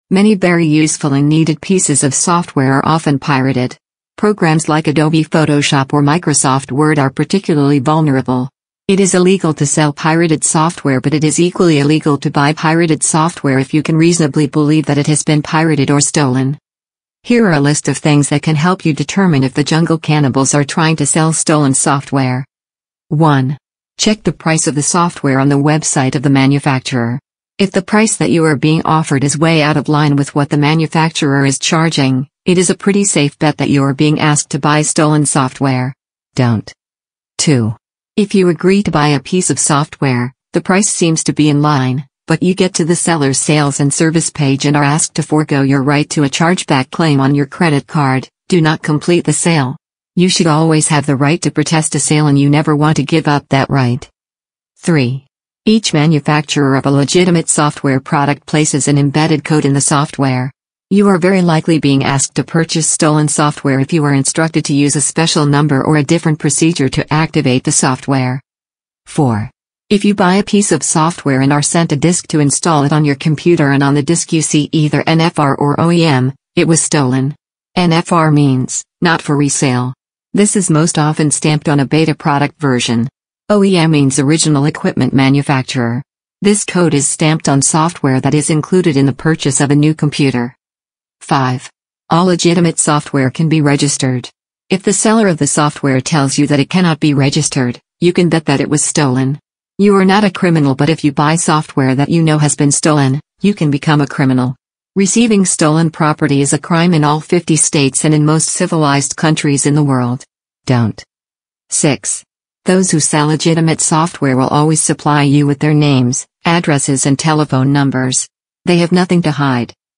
Each episode features insightful conversations with entrepreneurs, freelancers, and experts who share their personal stories, practical strategies, and lessons learned from building successful remote careers.